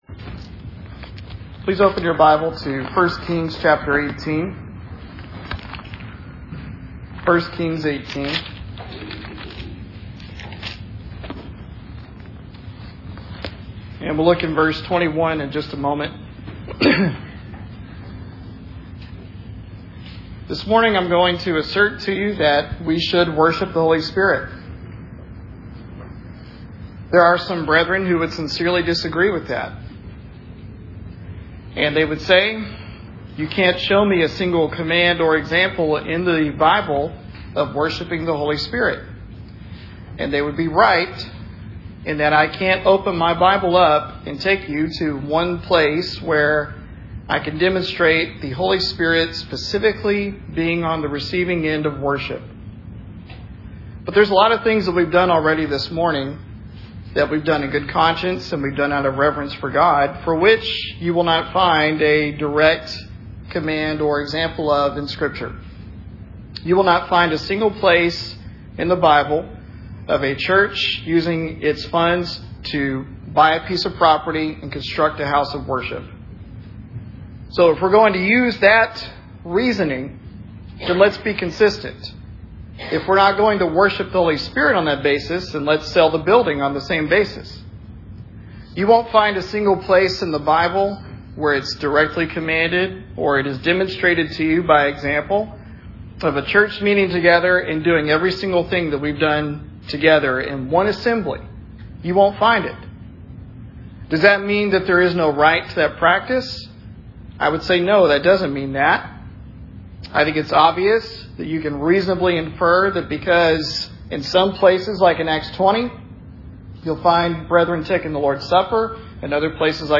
Sermon Recordings - 2024